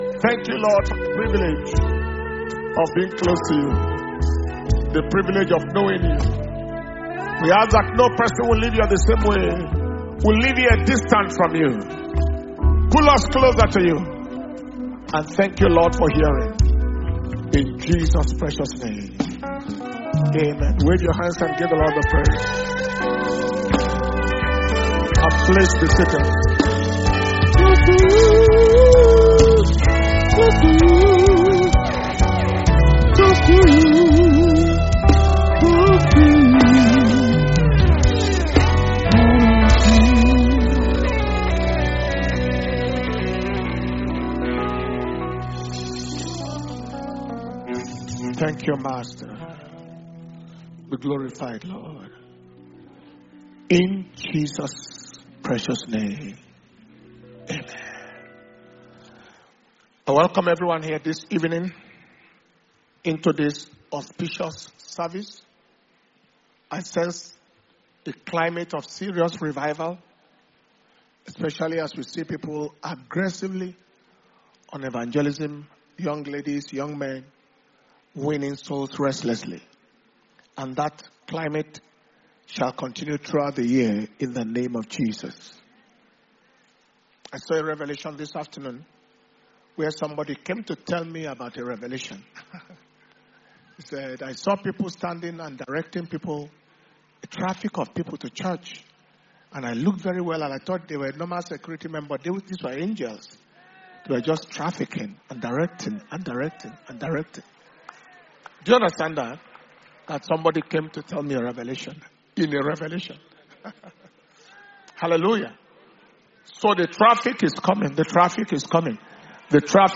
Power Communion Service - Wednesday 29th January 2025 Message